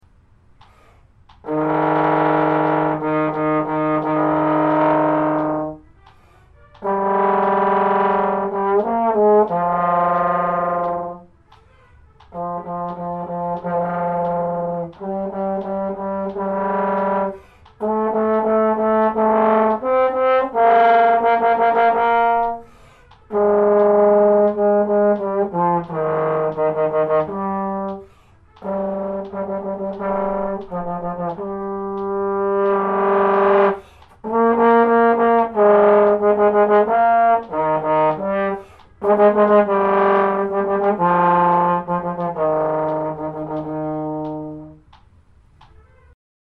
Fluttertongue Drill
fluttertongue-drill.mp3